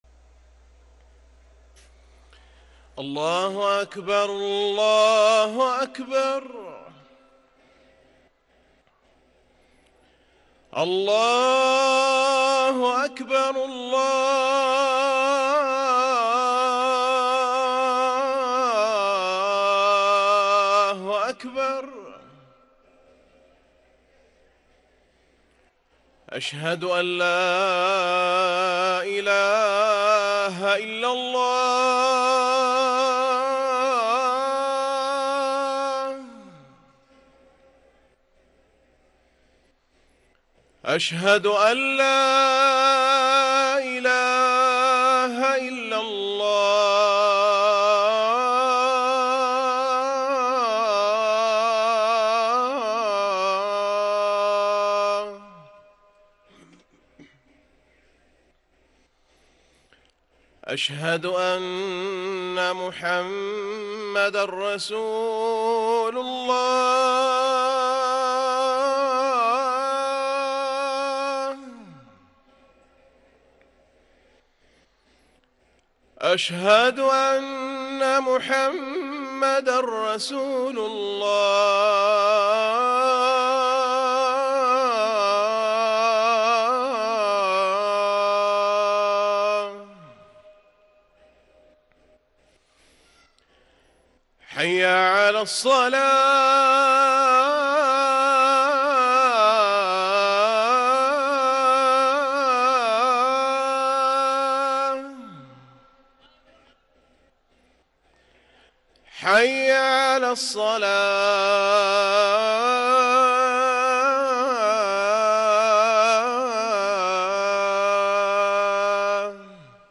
أذان العصر
ركن الأذان